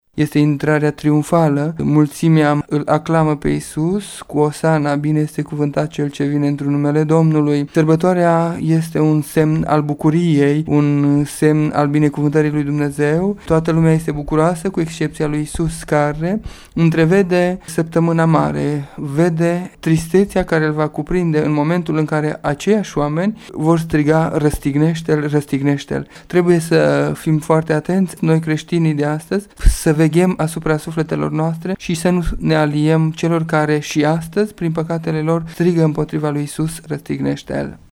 Acest lucru semnifică, de fapt, firea omenească, mereu schimbătoare, spune preotul greco-catolic din Tîrgu-Mureş